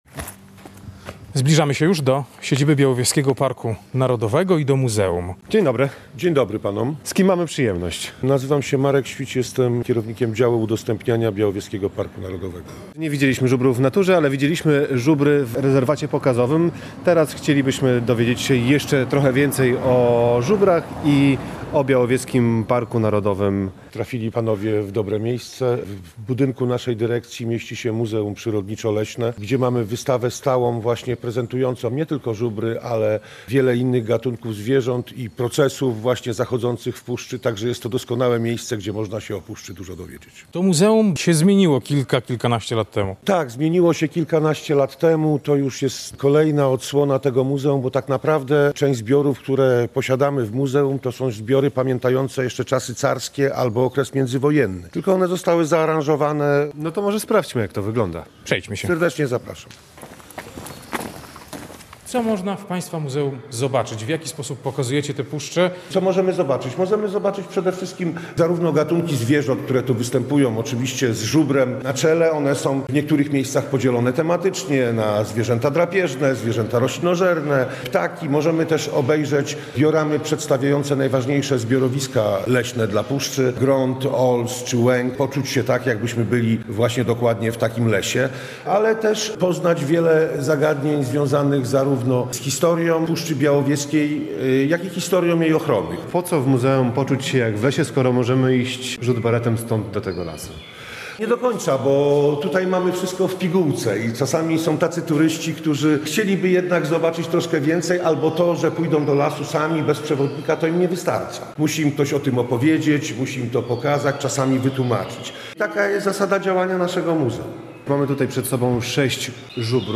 Nasi reporterzy z wizytą w Muzeum Przyrodniczo-Leśnym Białowieskiego Parku Narodowego | Pobierz plik.